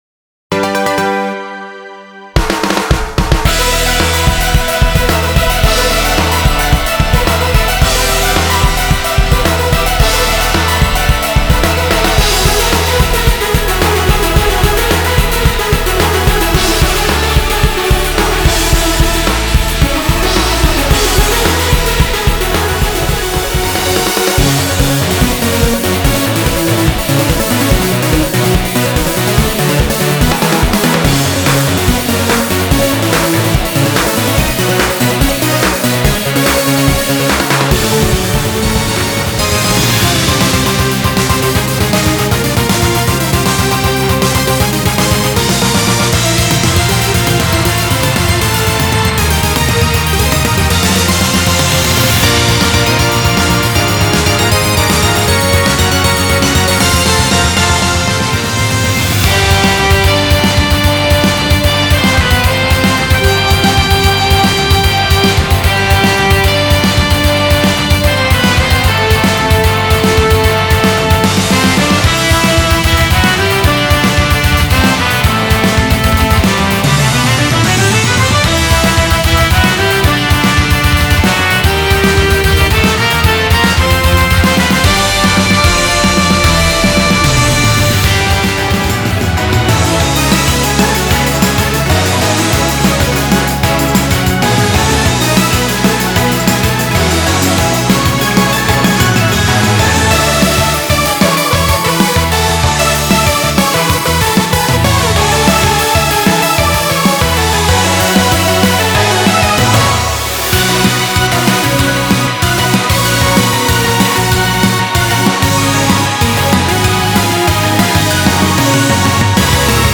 原曲を重視しつつ現代にパワーアップされたアレンジで、今、蘇ります！
種別 音楽CD